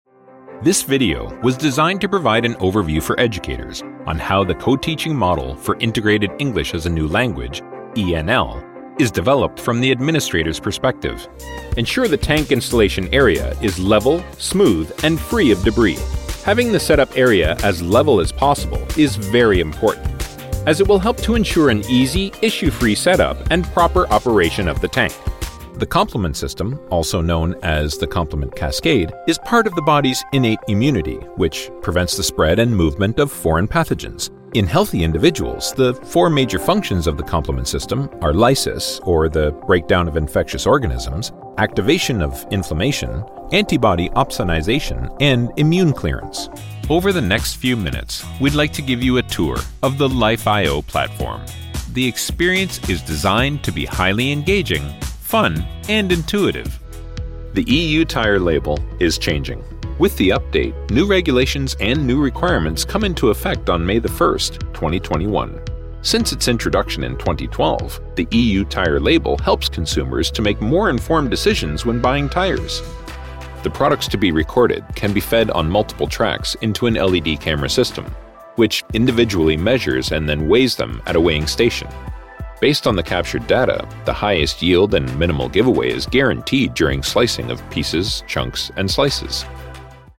a resonant, warm baritone voice with a neutral Canadian accent
Explainer Videos
My dedicated, broadcast-quality studio is Source Connect Certified* and features a Neumann TLM103 microphone, a Universal Audio Apollo X preamp, Audio Technical M50x Studio Headset, MacBook Pro running Adobe Audition, and a hard-wired ethernet connection with 1.5G speed.